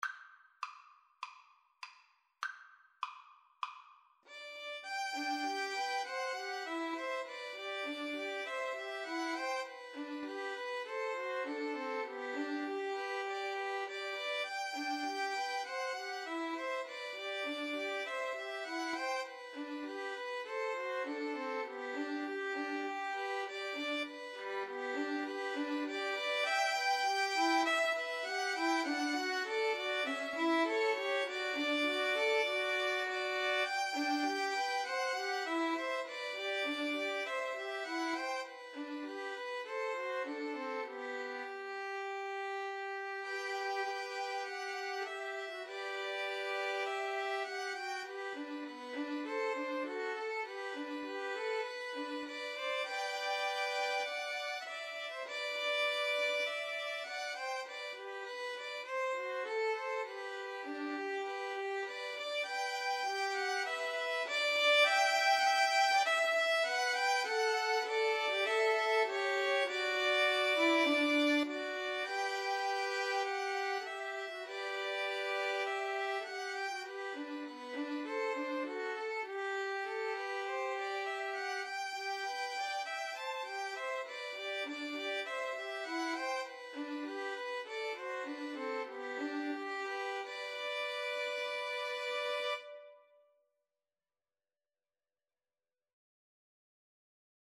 Free Sheet music for Violin Trio
Violin 1Violin 2Violin 3
G major (Sounding Pitch) (View more G major Music for Violin Trio )
Andante
4/4 (View more 4/4 Music)
Traditional (View more Traditional Violin Trio Music)
Scottish